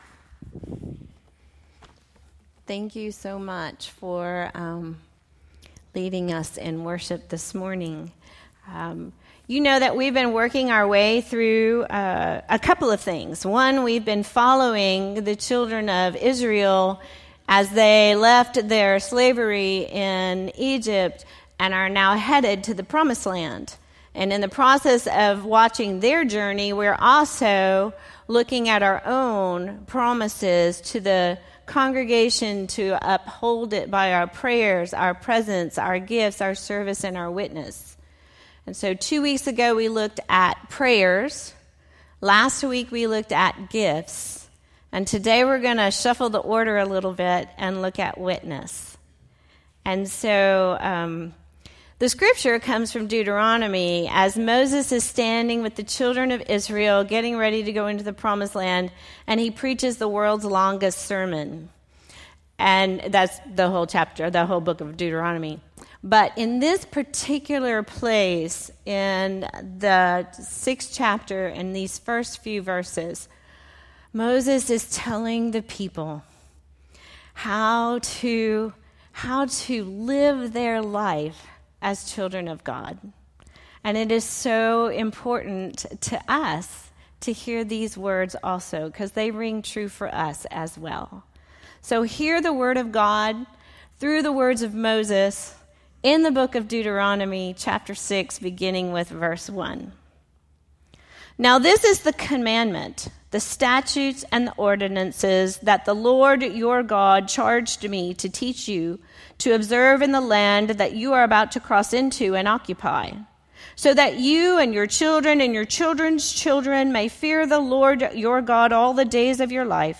Telling Our Faith Story – First Service